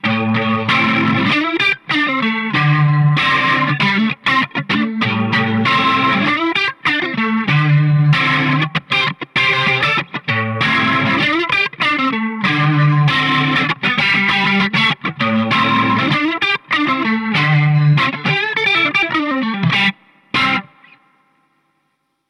Brillante Höhen und ein voller Clean-Sound sind für das Topteil kein Problem.
Der Blackface 22 Reverb liefert einen sehr schönen, leicht angezerrten Sound mit vielen harmonischen Obertönen.
TAD Blackface 22 Reverb Test Klangbeispiele
Der Verstärker überzeugt in den Klangtests durch seine große Flexibilität, die Sounds von cleanem Pop bis in den verzerrten Rock Bereich ermöglicht.